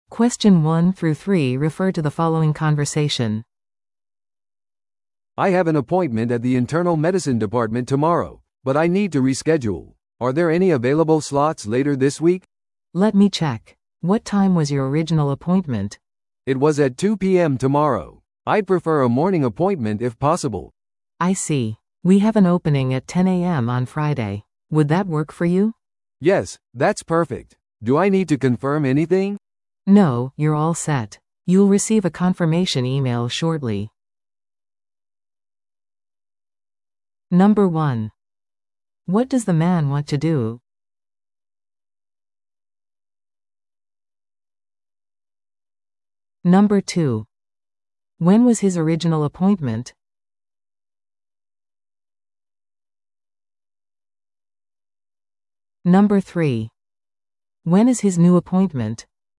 TOEICⓇ対策 Part 3｜病院の予約変更についての会話 – 音声付き No.64
No.1. What does the man want to do?